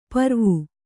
♪ parvu